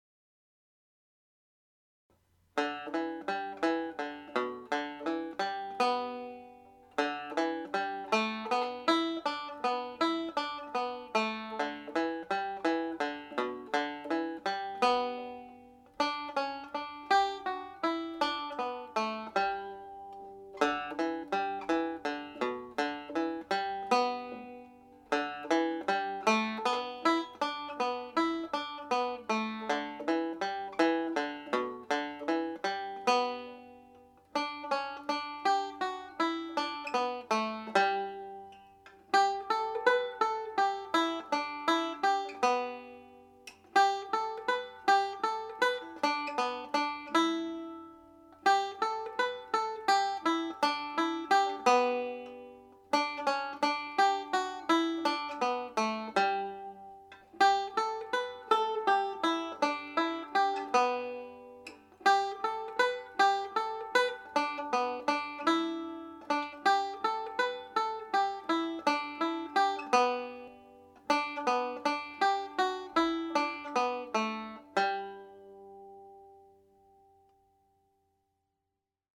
The Butlers of Glen Avenue played slowly